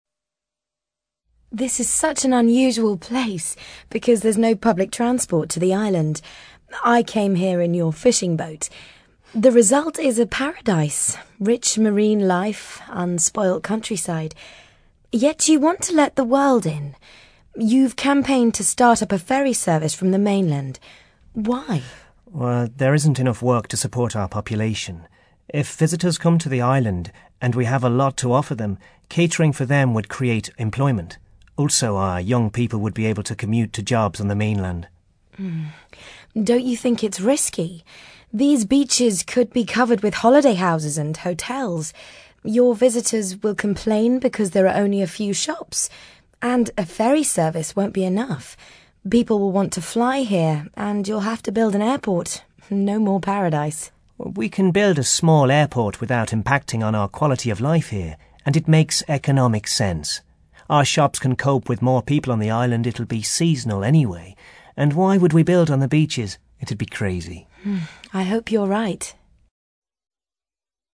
On the radio, you hear a visitor talking to a man about the remote island where he lives.